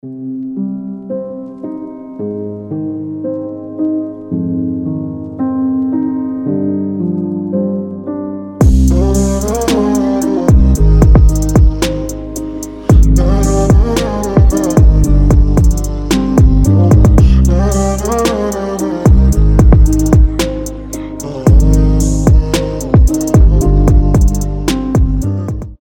• Качество: 320, Stereo
спокойные
пианино
медленные
Chill Trap
Приятный чил-хоп на звонок